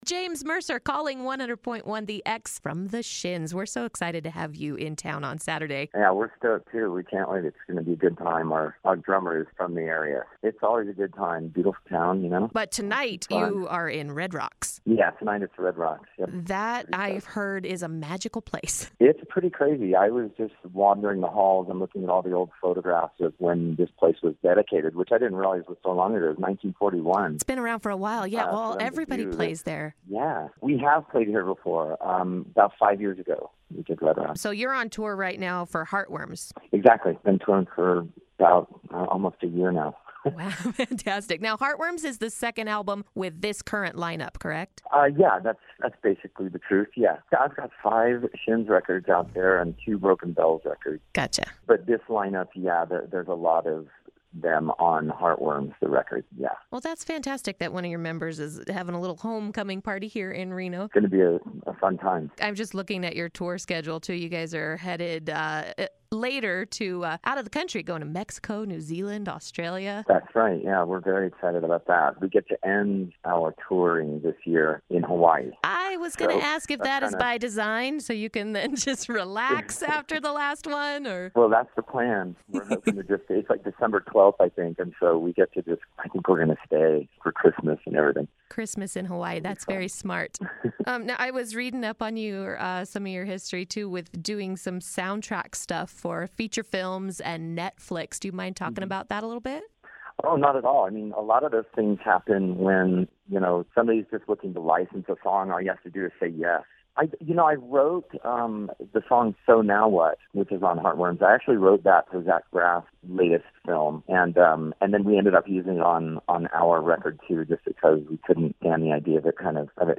Interview: James Mercer of The Shins